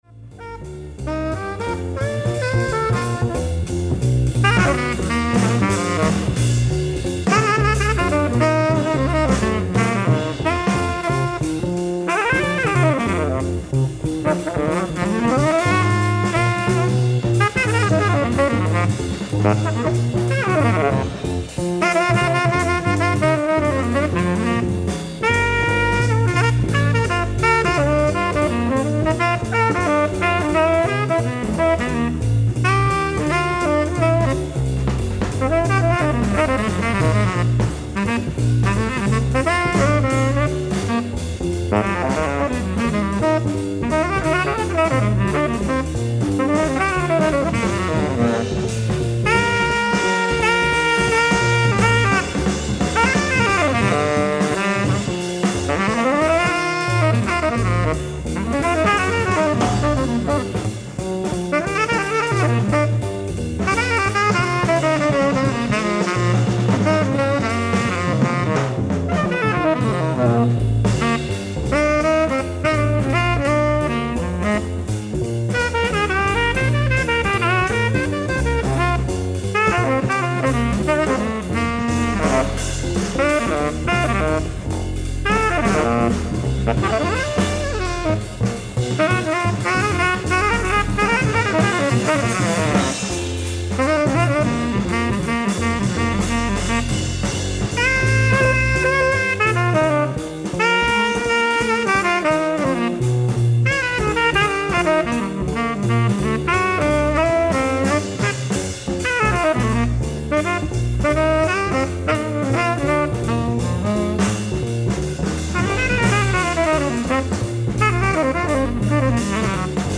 ライブ・アット・フローニンゲン、オランダ 10/13/1997
※試聴用に実際より音質を落としています。